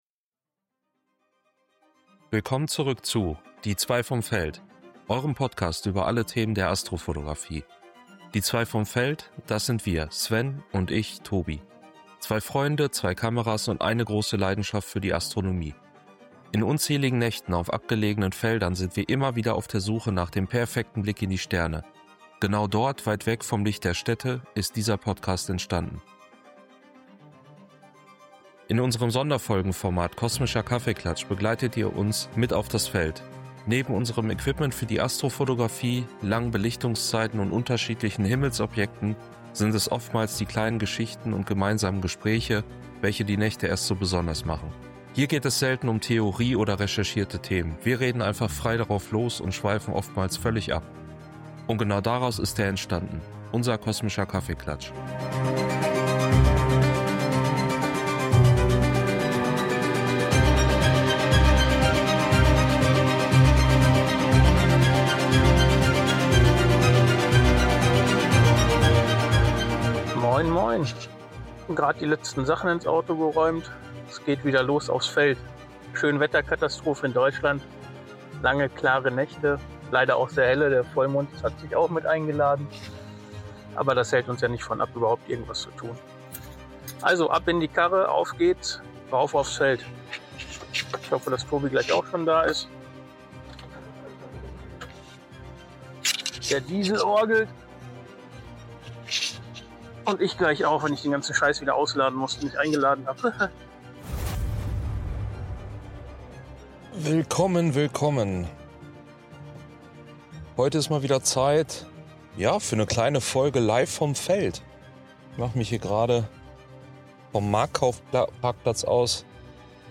Beschreibung vor 1 Woche In unserem Sonderfolgenformat Kosmischer Kaffeeklatsch begleitet ihr uns mit auf das Feld.
Hier geht es selten um Theorie oder recherchierte Themen, wir reden einfach frei drauf los und schweifen oftmals völlig ab.